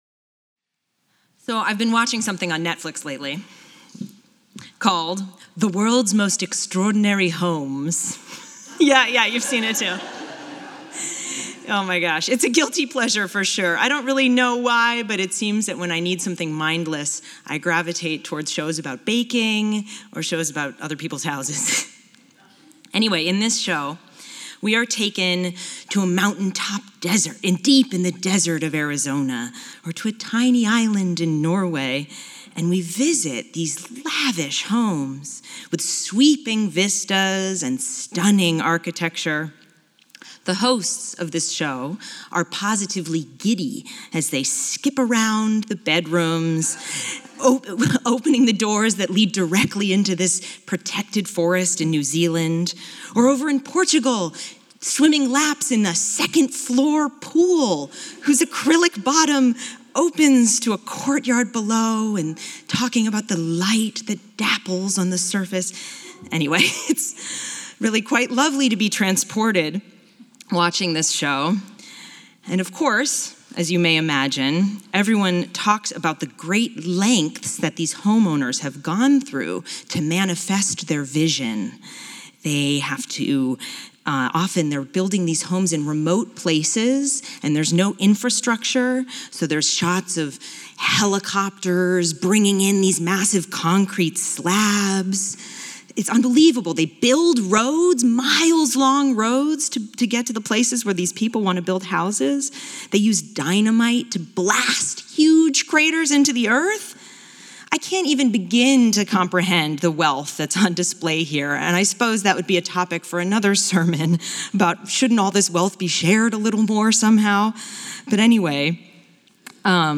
In this sermon I’ll share my reflections on the power of “night vision.”